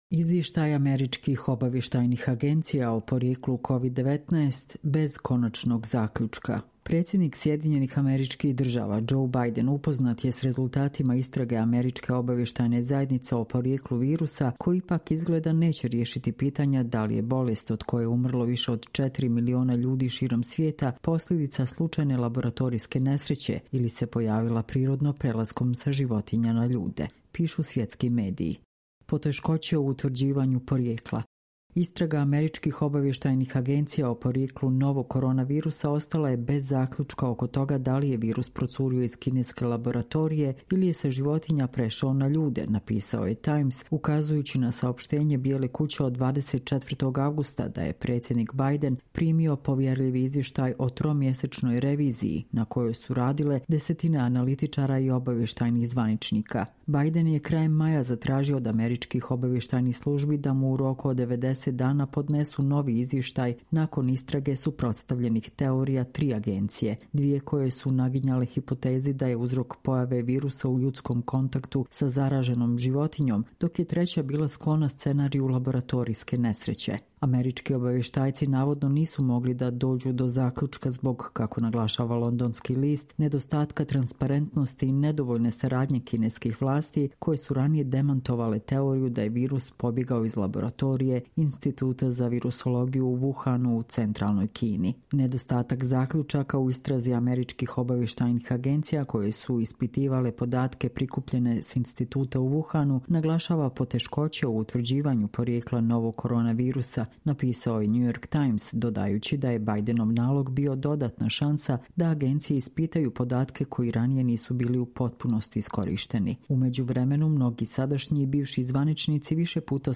Čitamo vam: Izvještaj američkih obavještajnih agencija o porijeklu COVID-19 bez konačnog zaključka